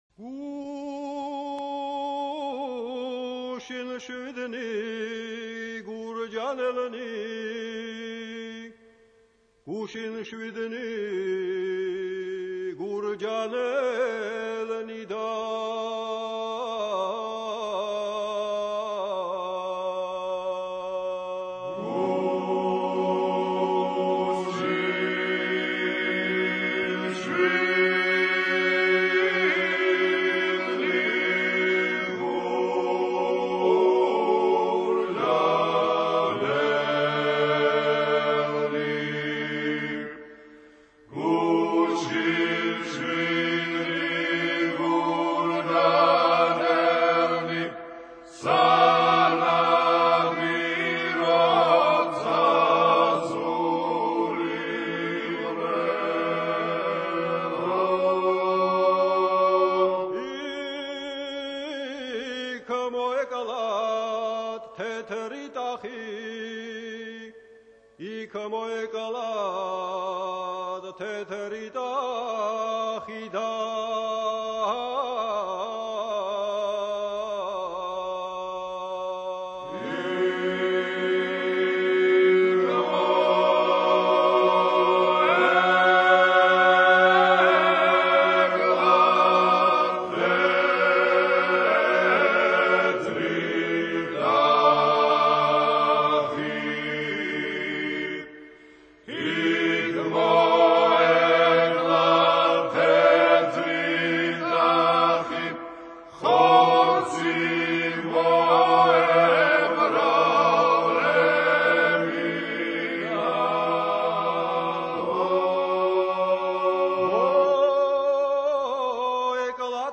A work song.